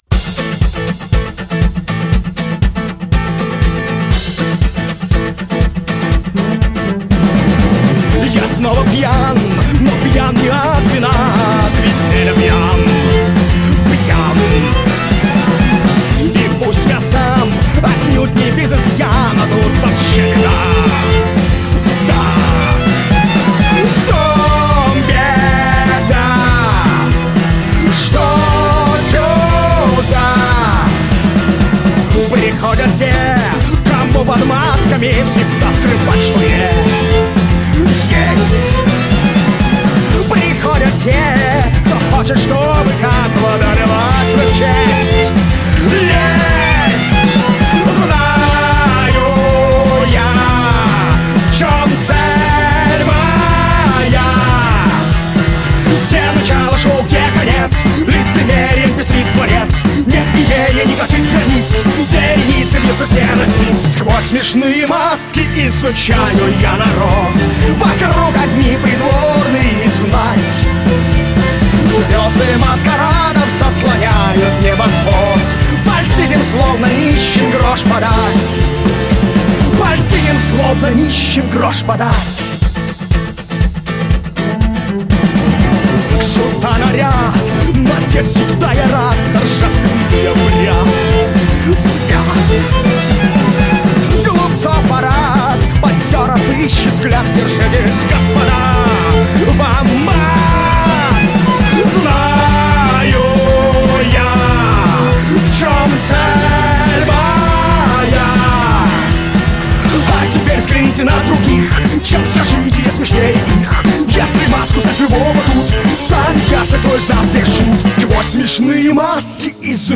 Рок
Жанр: Жанры / Рок